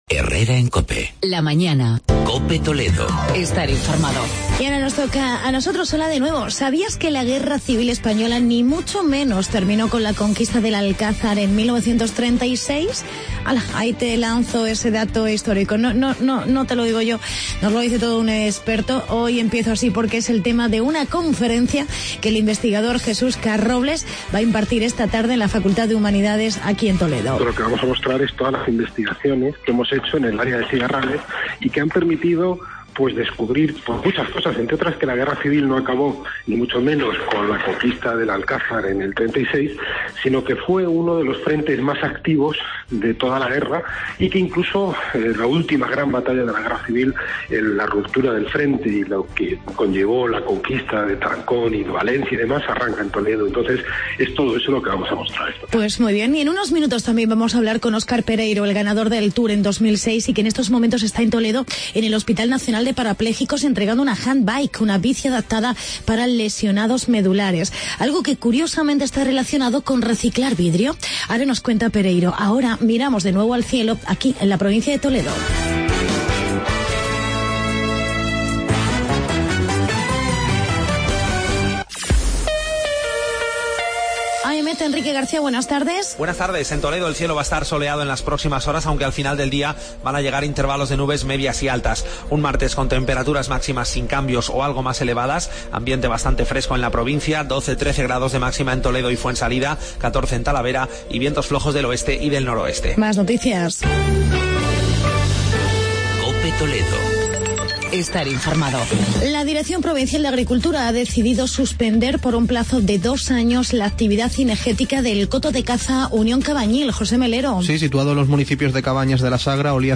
Actualidad y entrevista Oscar Pereiro, ex-ciclista, ganador del Tour de Francia 2006.